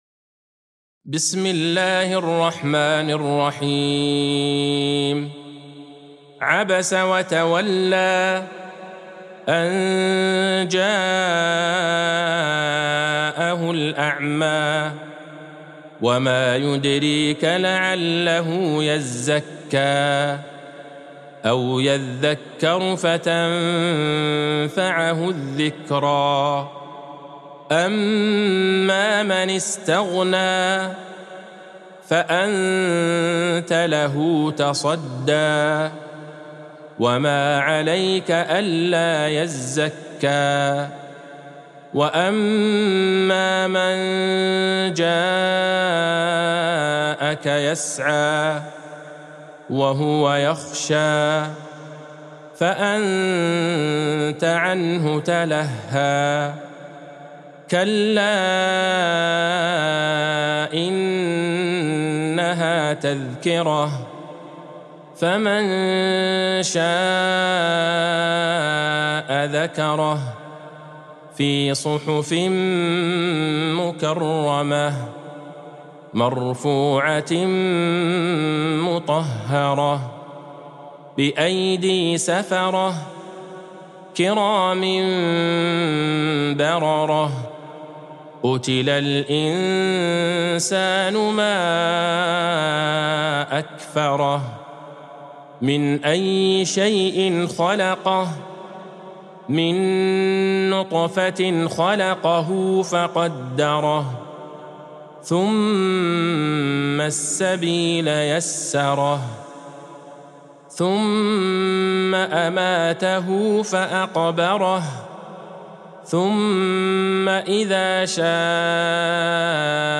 سورة عبس Surat Abasa | مصحف المقارئ القرآنية > الختمة المرتلة ( مصحف المقارئ القرآنية) للشيخ عبدالله البعيجان > المصحف - تلاوات الحرمين